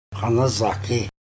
chant du Rêveur